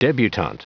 Prononciation du mot debutante en anglais (fichier audio)
Prononciation du mot : debutante